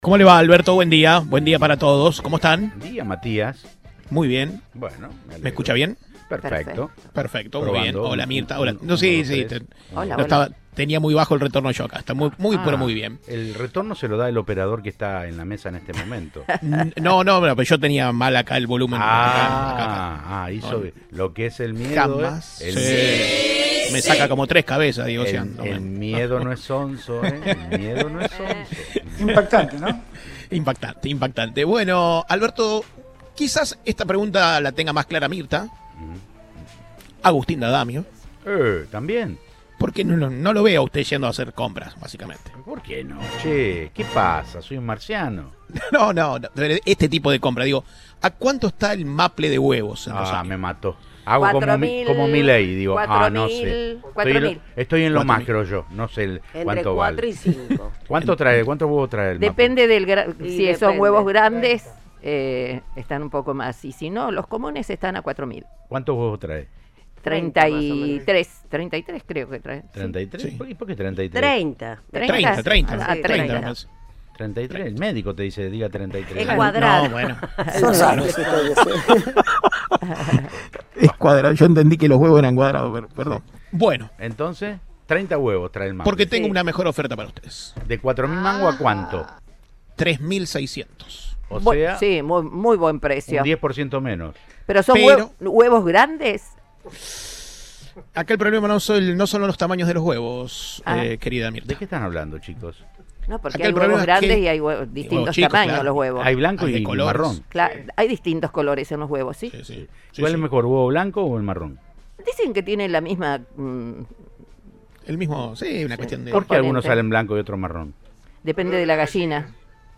Así lo contó al móvil de Cadena 3 Rosario, en Siempre Juntos: "La gente viene a velocidad, es un peligro que haya pasado tanto tiempo sin que lo hayan arreglado".